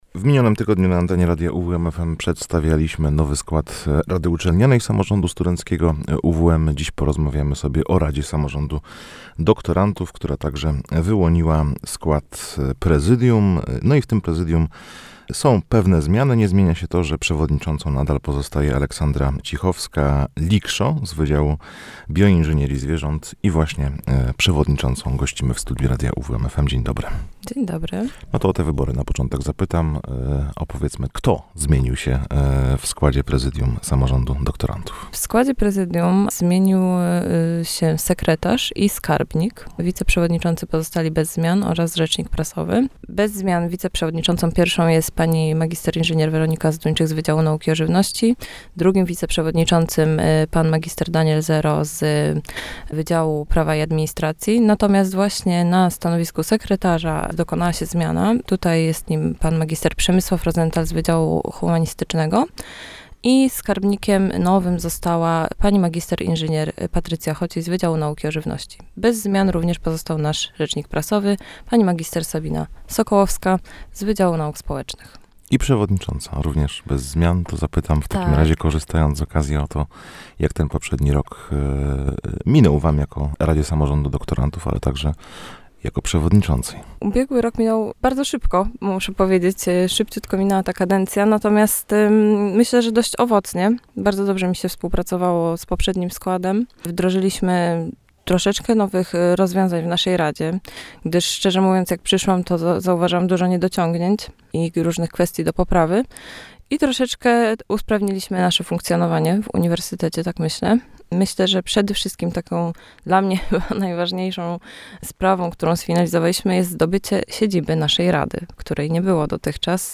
W studiu Radia UWM FM podsumowała ostatnie miesiące jej działalności.